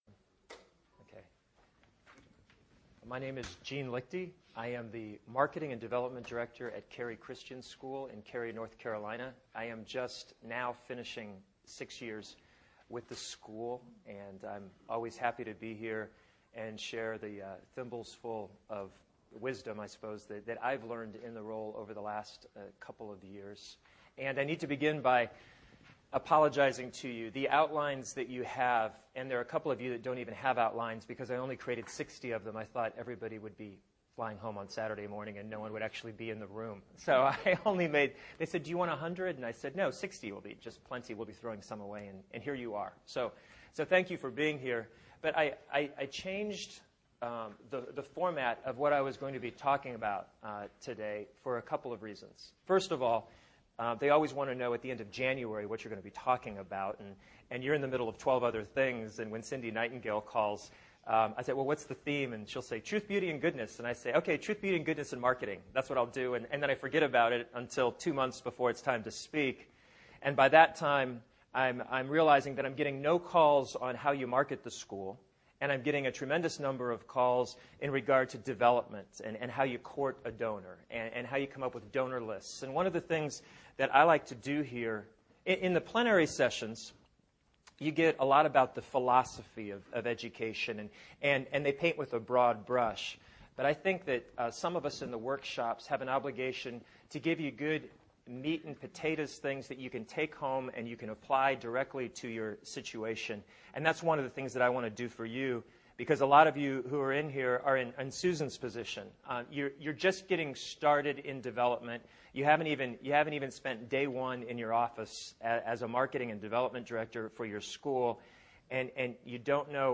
2008 Workshop Talk | 0:56:19 | Fundraising & Development, Marketing & Growth